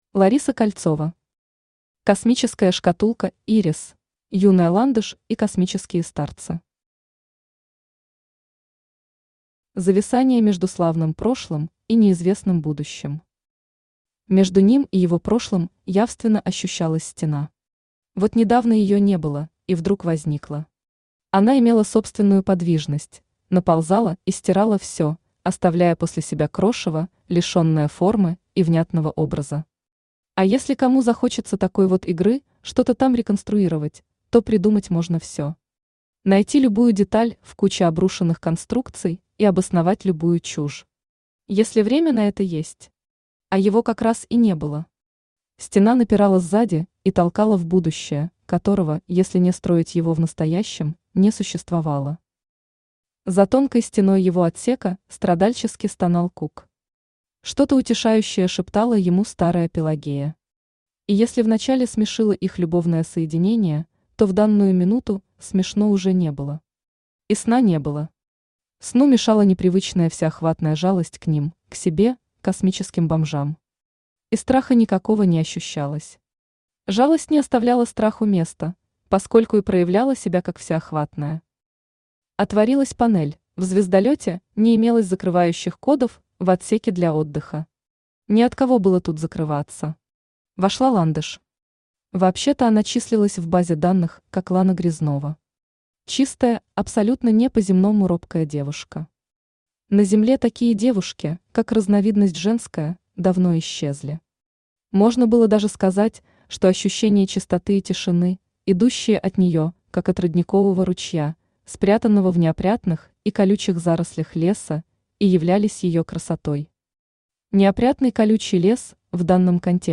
Aудиокнига Космическая шкатулка Ирис Автор Лариса Кольцова Читает аудиокнигу Авточтец ЛитРес.